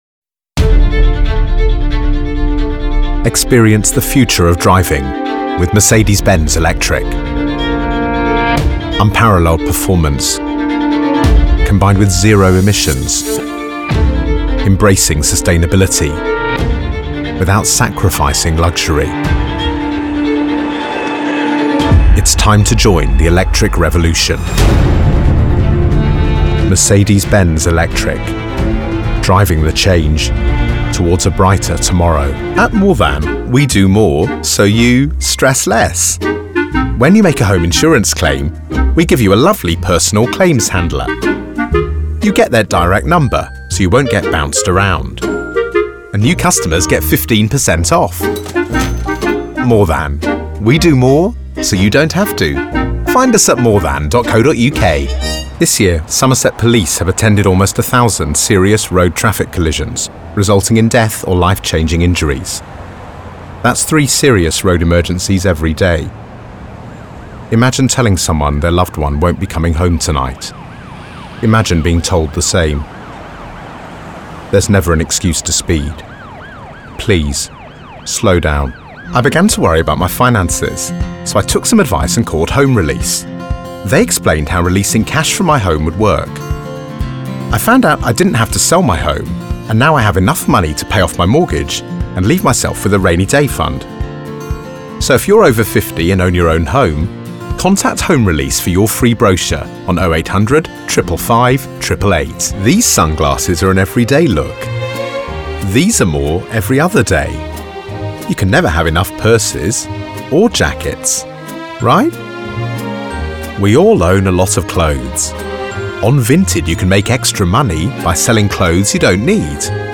Browse professional voiceover demos.
I have a versatile style to my voiceover work. It can be powerful and commanding to casual and nonchalant. I can be the traditional professional announcer type to the guy next door....